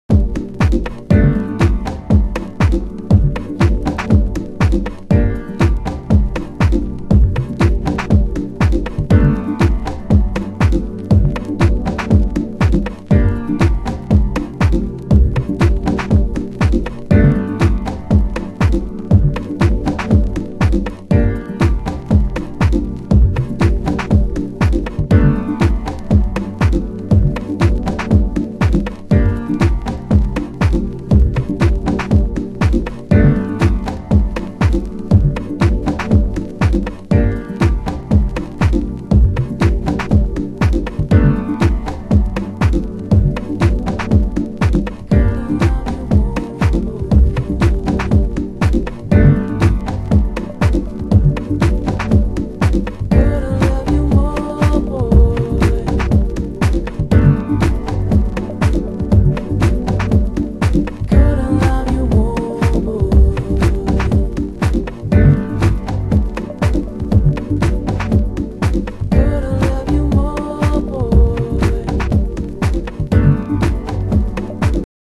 盤質：軽いスレ、チリパチノイズ有